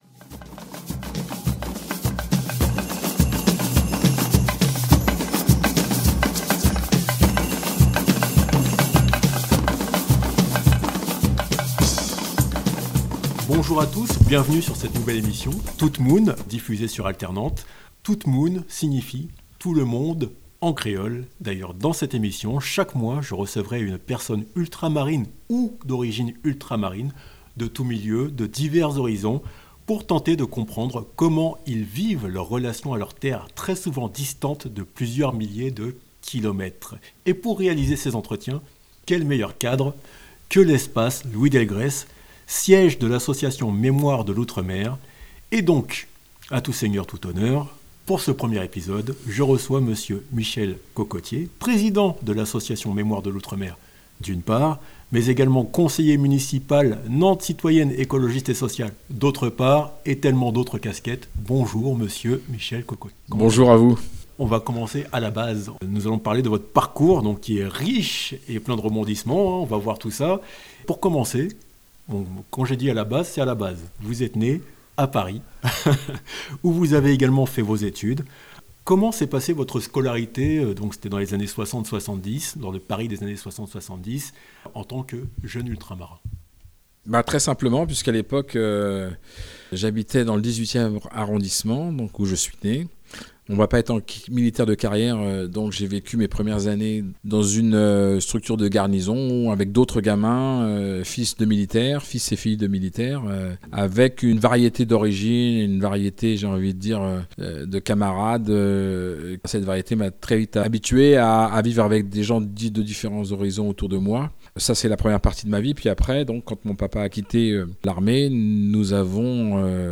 tout moun - cocotier - itw - mix.mp3